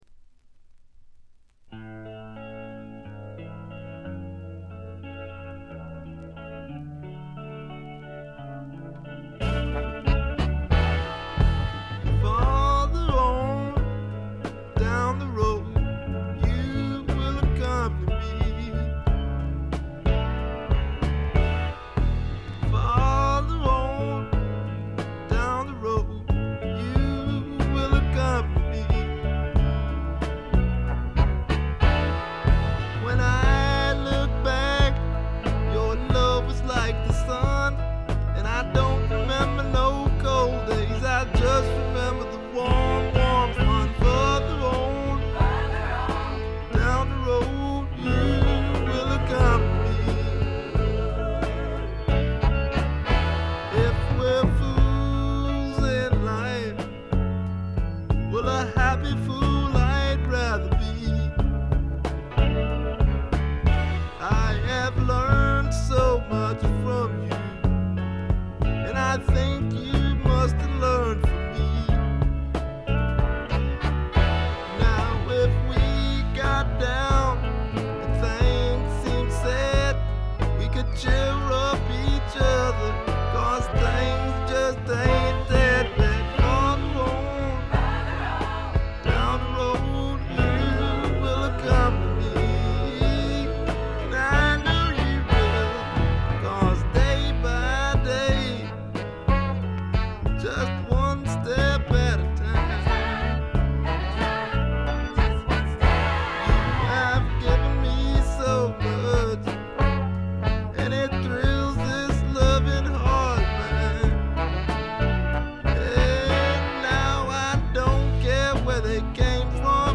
軽微なチリプチがほんの少し。
いうまでもなく米国スワンプ基本中の基本。
試聴曲は現品からの取り込み音源です。